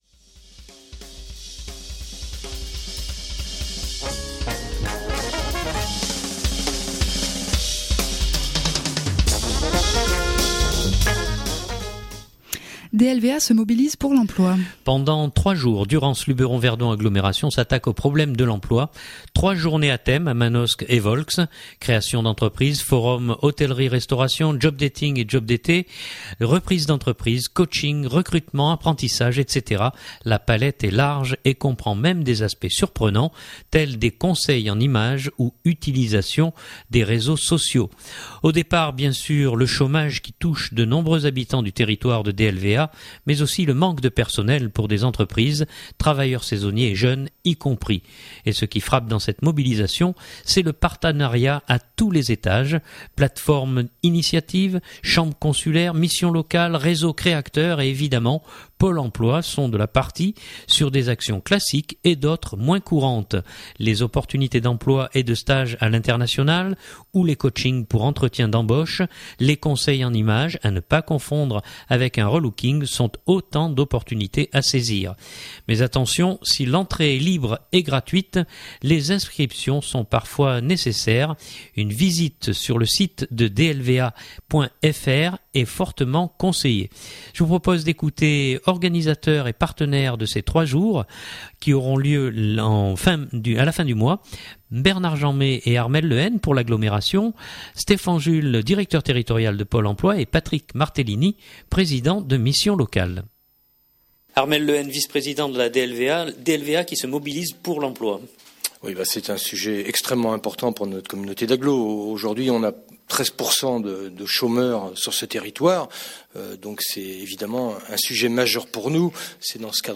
Je vous propose d’écouter organisateurs et partenaires de ces trois jours